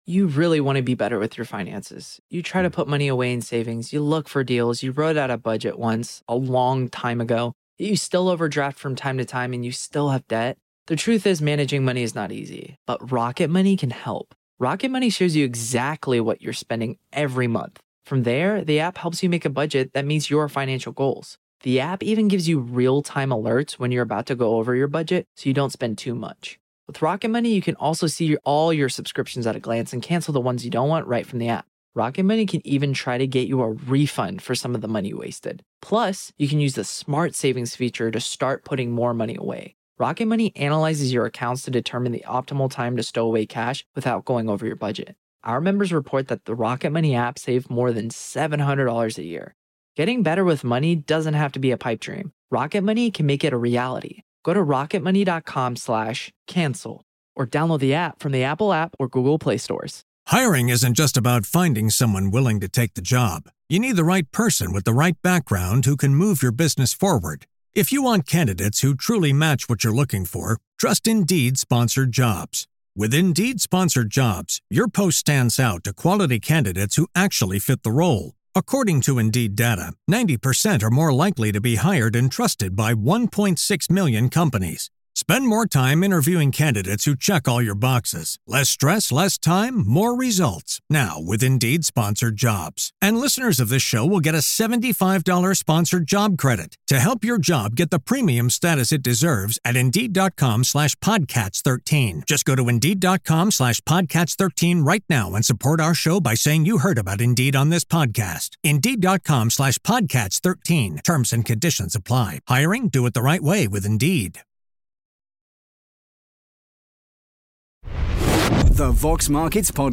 In this upbeat interview